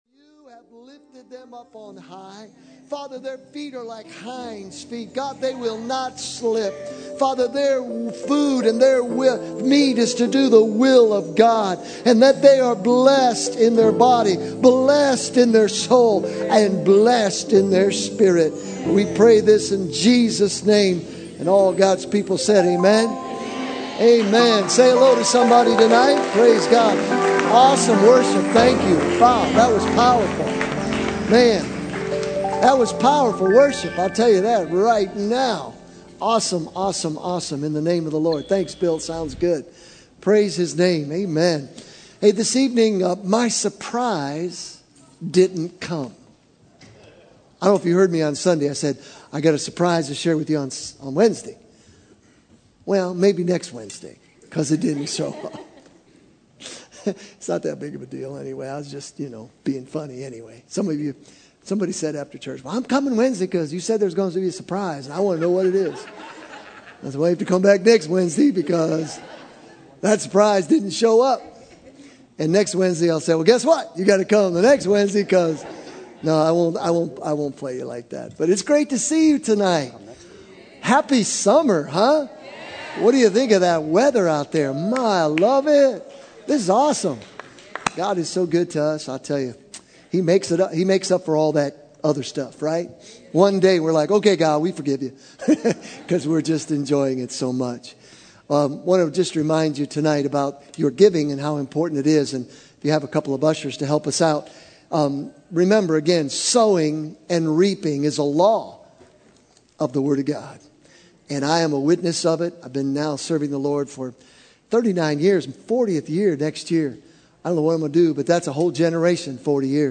November 24, 2013 - Soaking Service - Sunday 7pm